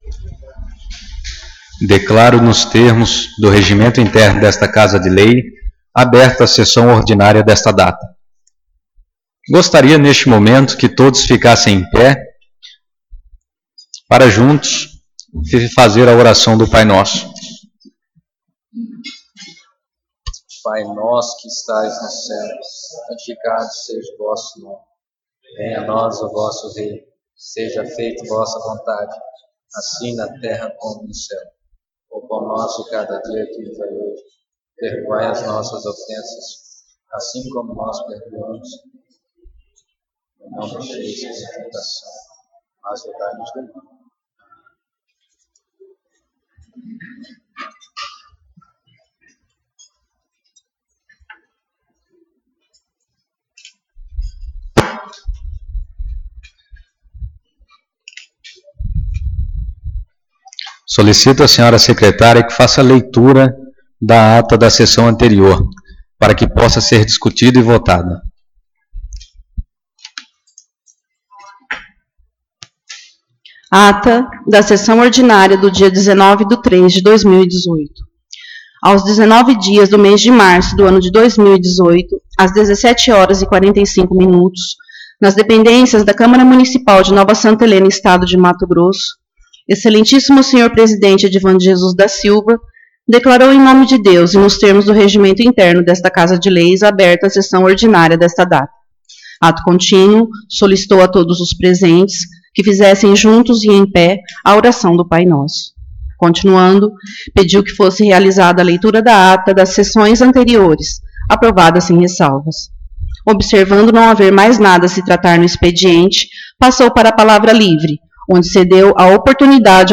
Sessão Ordinária 02/04/2018